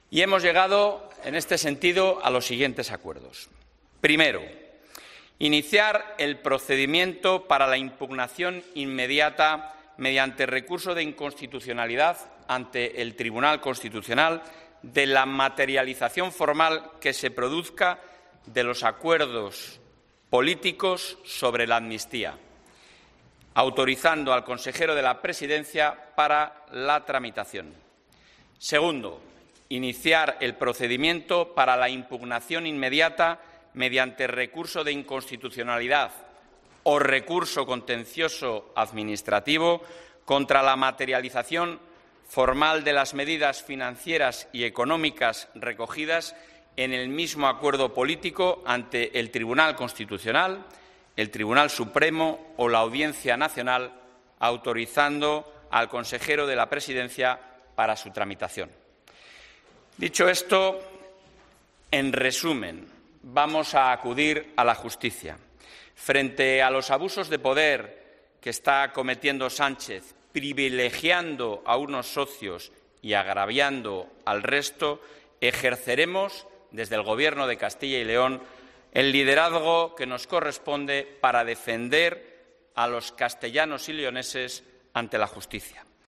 Alfonso Fernández Mañueco, tras la reunión extraordinaria del Consejo de Gobierno de Castilla y León